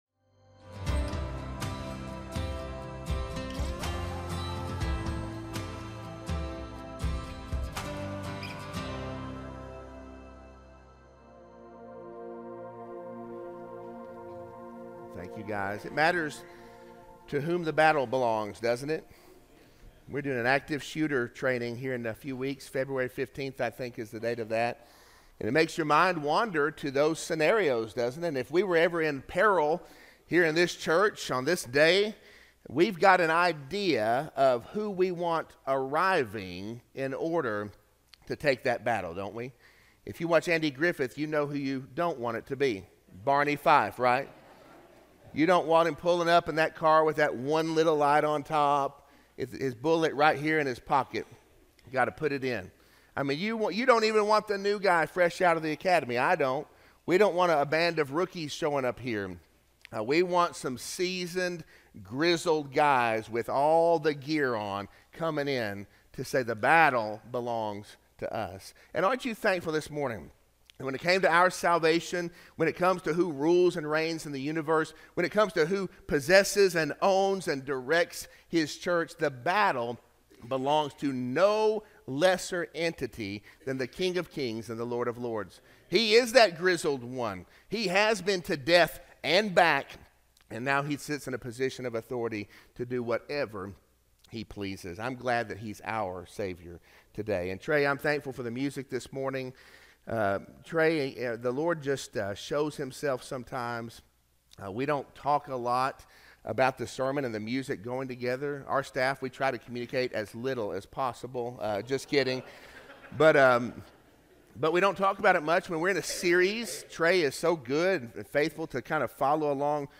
Sermon-1-12-25-audio-from-video.mp3